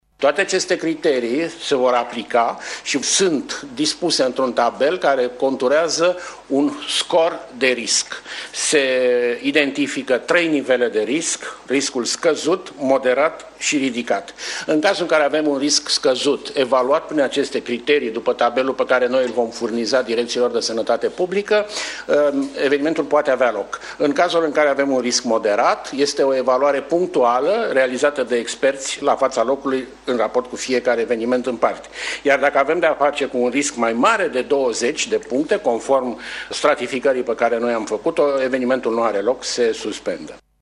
Secretarul de stat Horațiu Moldovan arată că sunt 3 scoruri de risc, după care se face evaluarea și se ia decizia în cauză: